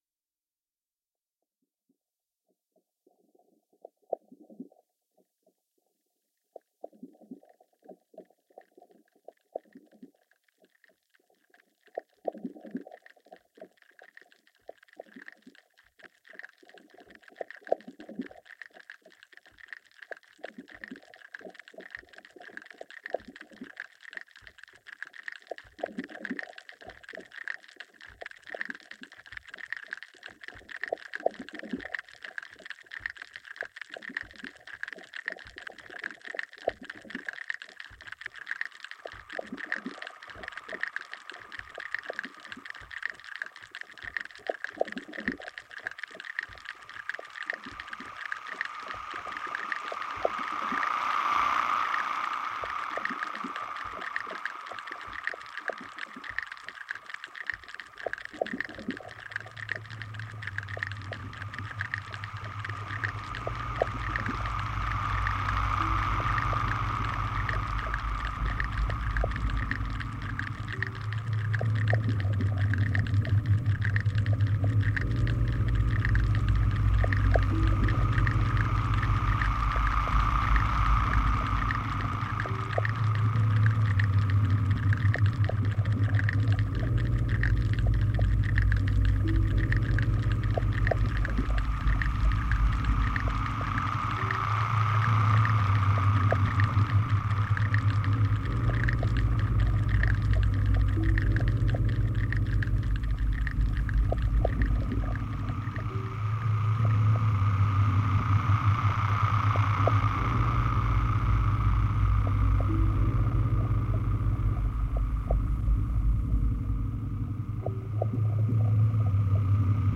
Hydrophone shrimp recording, Sri Lanka
I decide to work with the sound in a "musique concrète" way.